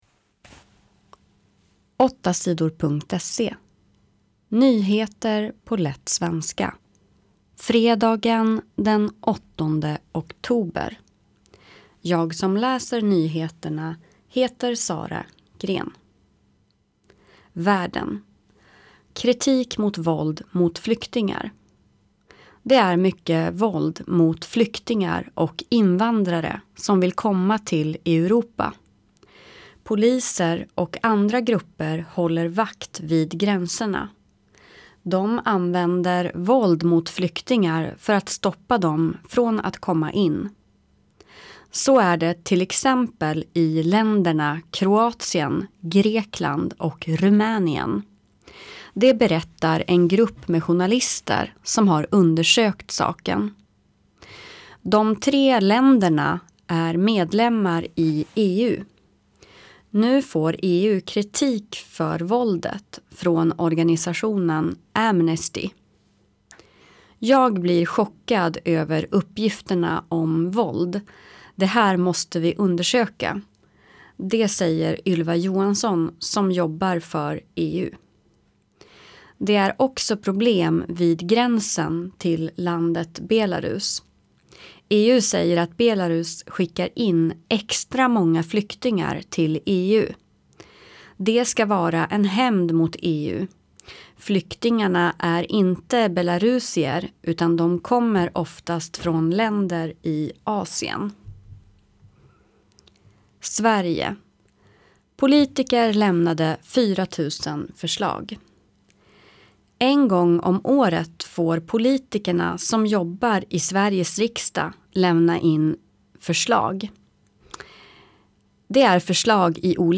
Nyheter på lätt svenska den 8 oktober